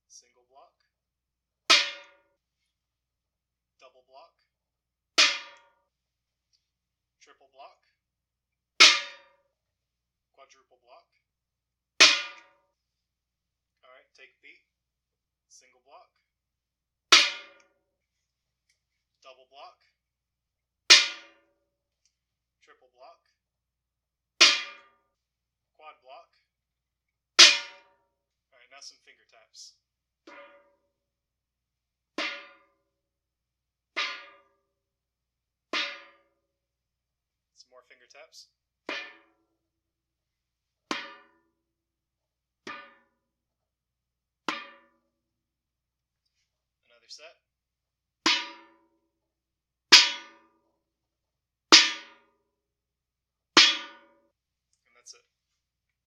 Does anyone know where I can get a sound like the clanging percussion here?
So I recorded a few hits of my own, using one of those huge Christmas popcorn tins (it was left over from Christmas) and a couple of Mega Blocks.
rawcanblocks.ogg